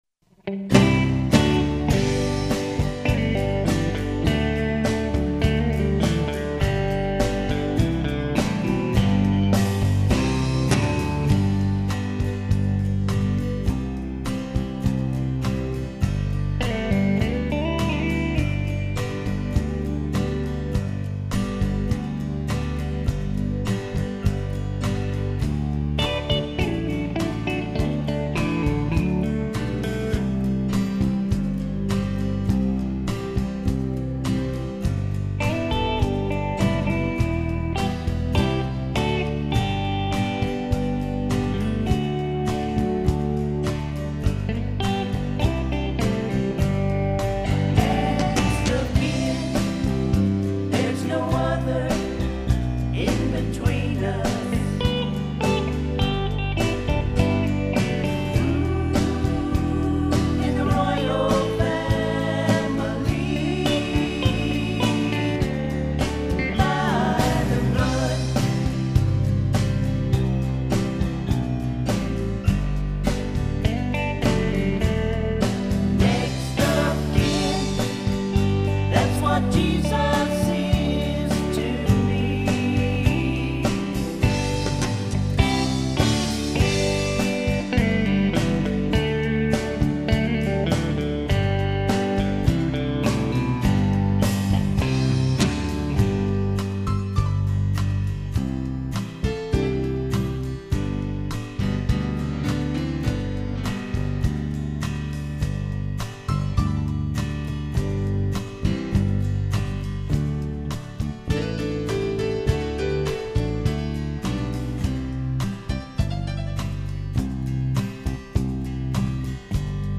Christian Gospel Music Next of Kin Next of Kin Many people, they spend a lifetime forever searching, Who they are, their family history.